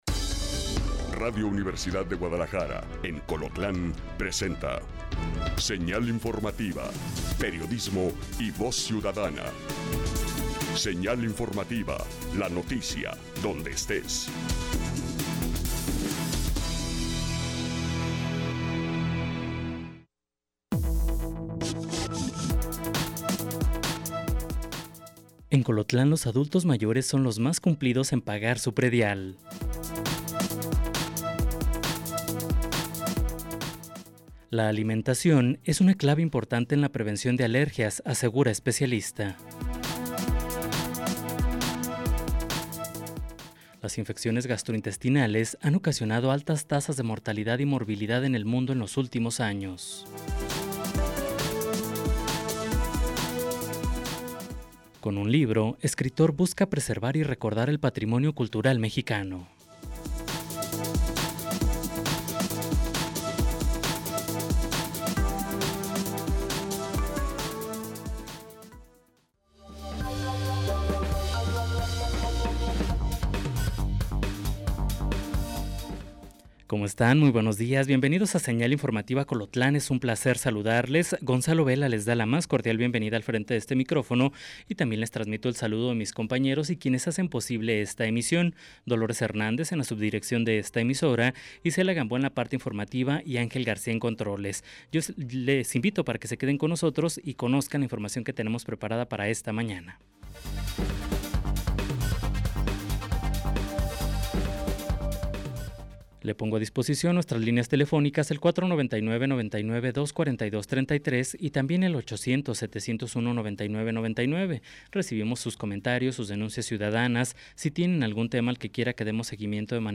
En este noticiero, nos enfocamos en las noticias locales que afectan directamente su vida y su entorno. Desde políticas y eventos comunitarios hasta noticias de última hora y reportajes especiales.